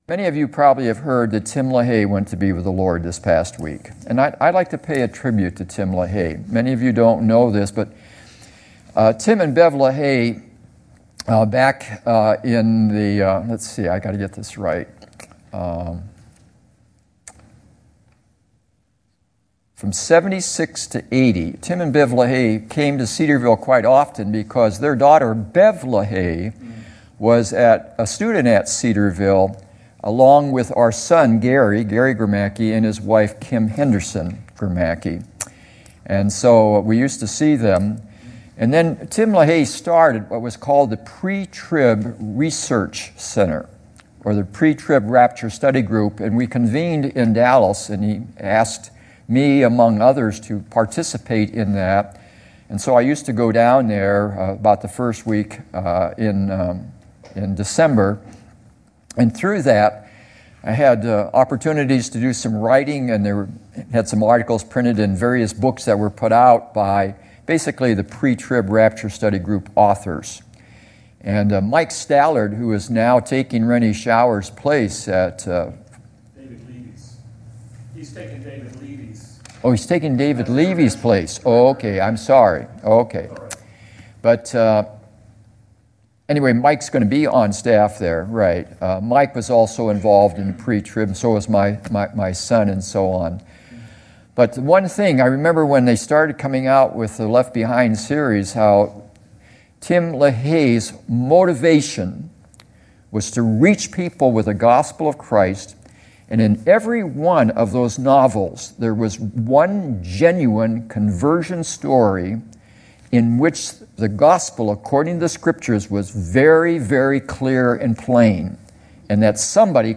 2016 Categories Sunday Evening Message Download Audio Download Notes Hebrews 2:10-18 Previous Back Next